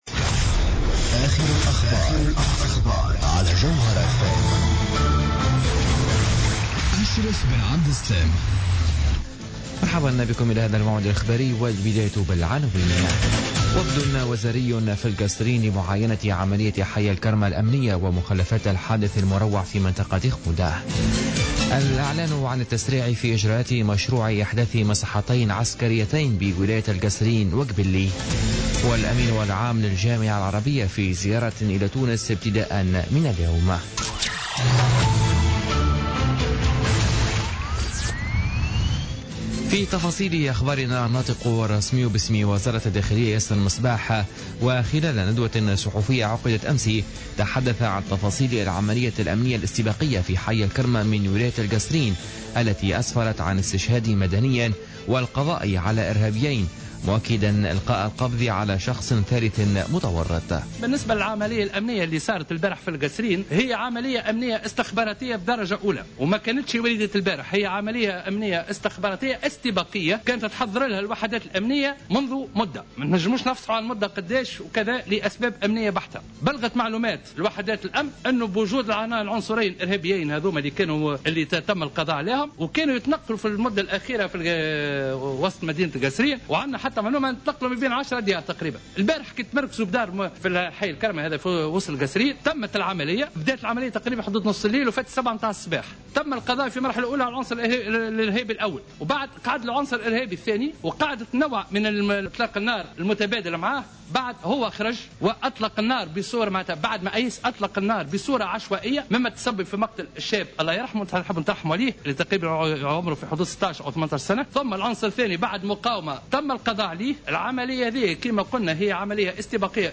Journal Info 00h00 du jeudi 1er septembre 2016